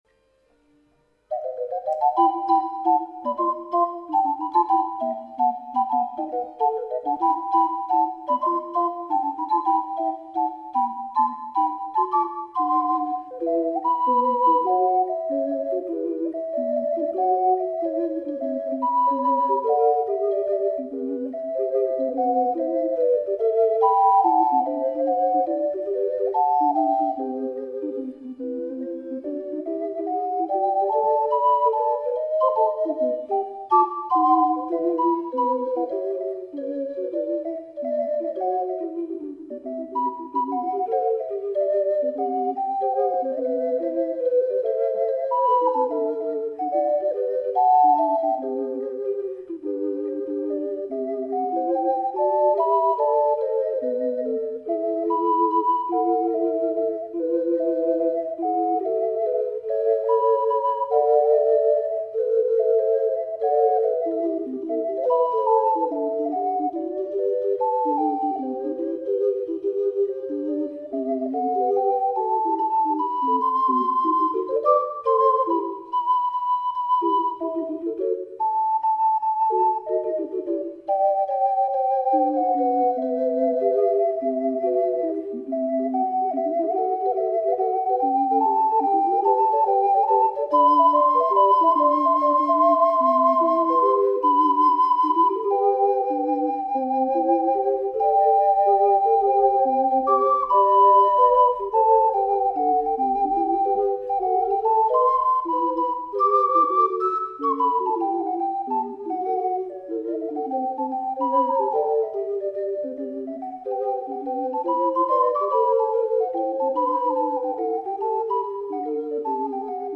試奏